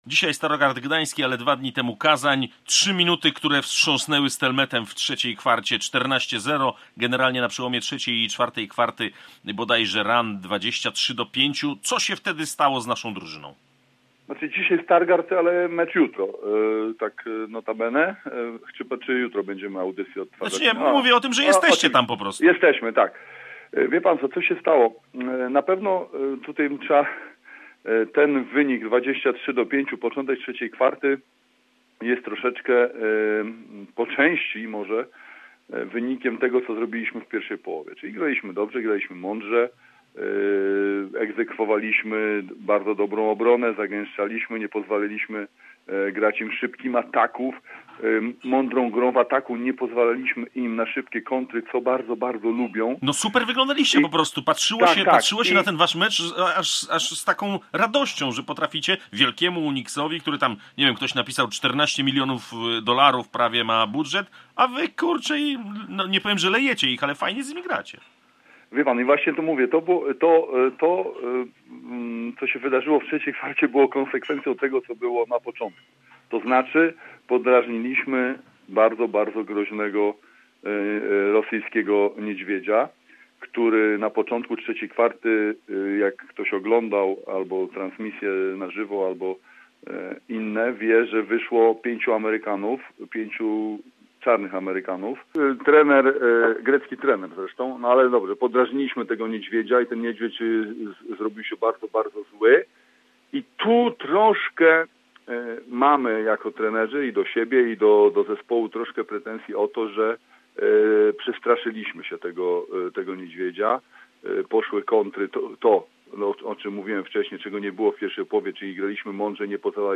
dzwoniąc do Starogardu